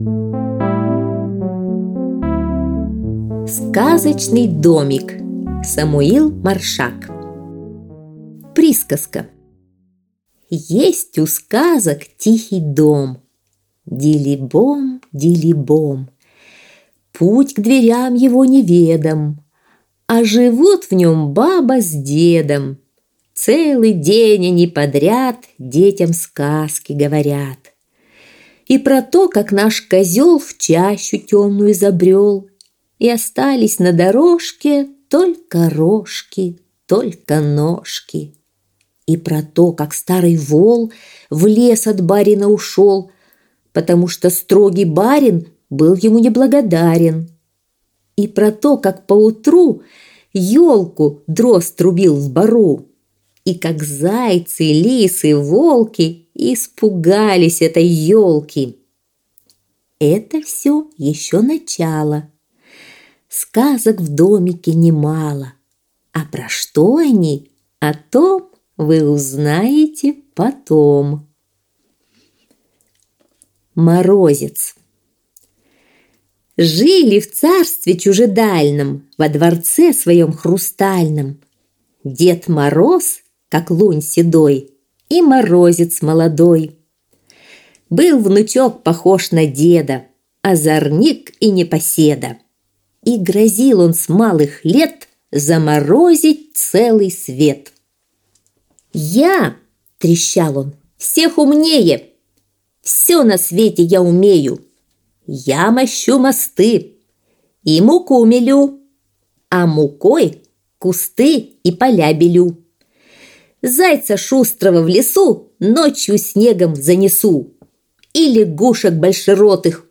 Сказочный домик - аудиосказка Маршака - слушать онлайн